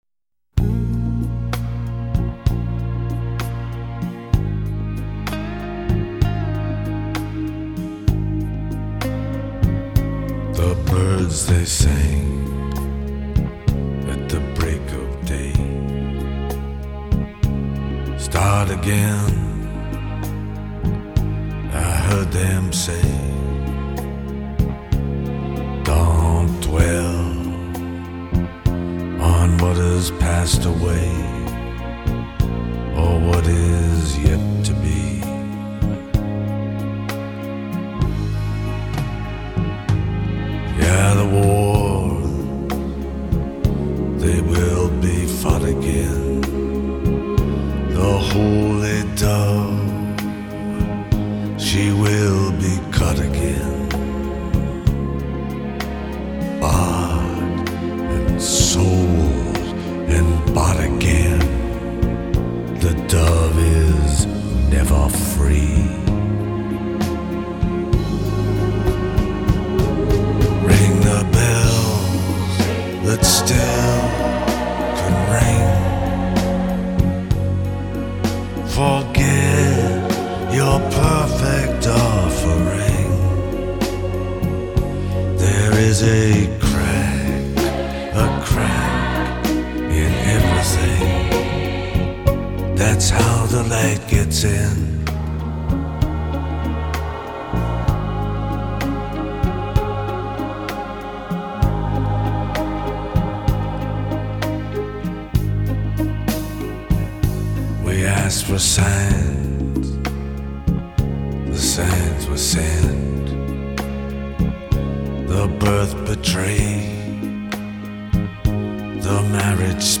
Soul музыка